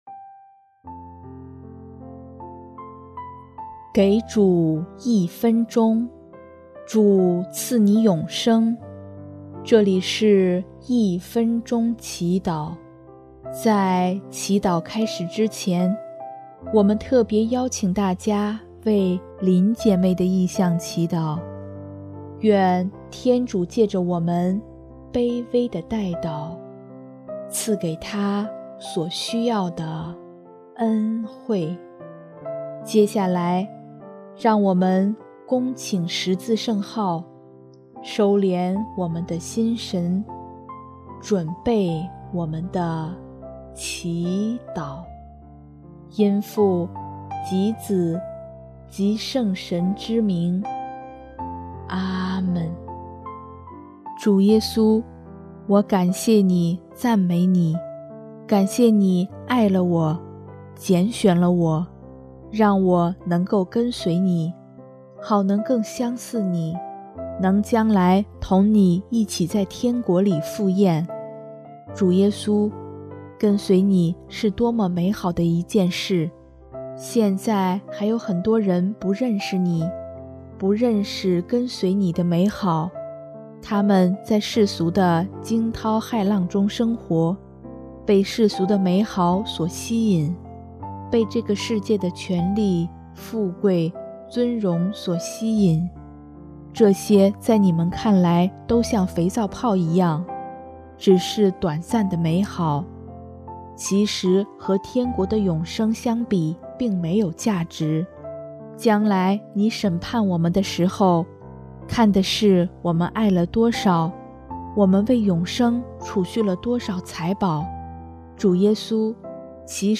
【一分钟祈祷】|8月24日 为永生储蓄财宝
音乐： 主日赞歌《舍弃》